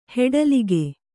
♪ heḍalige